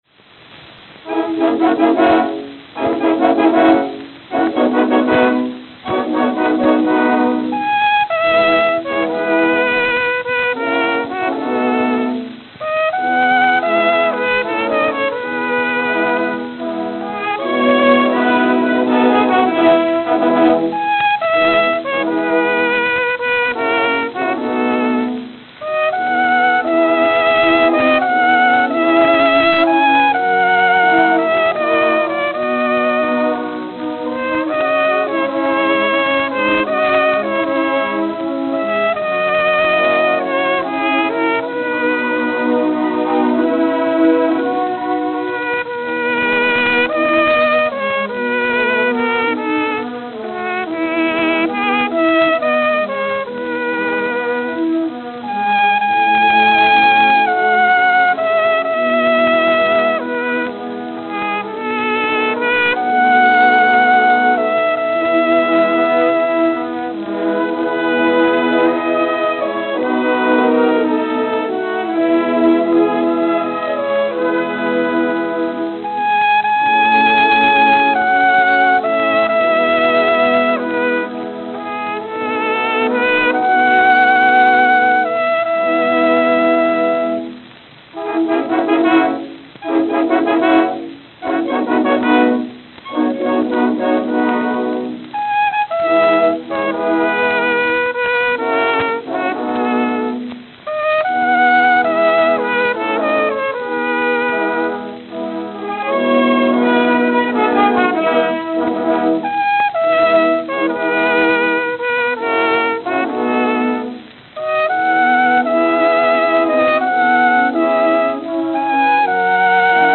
Berlin, Germany Berlin, Germany